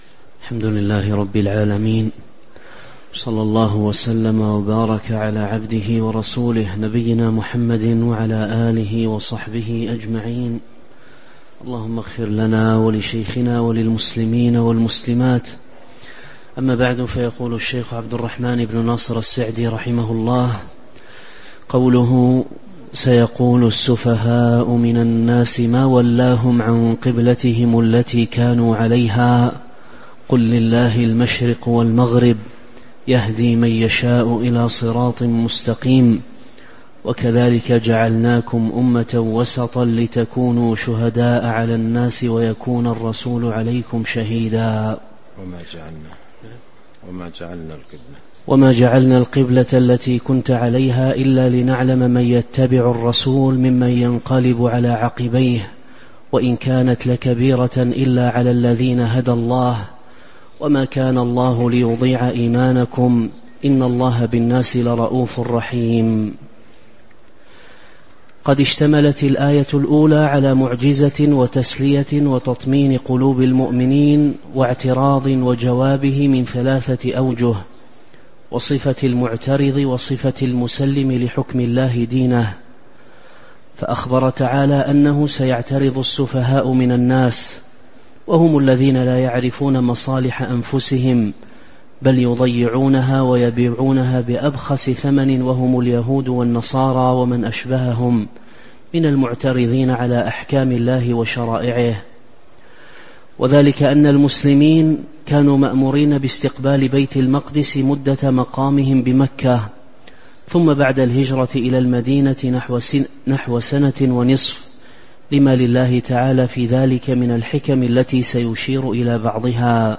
تاريخ النشر ١ جمادى الآخرة ١٤٤٦ هـ المكان: المسجد النبوي الشيخ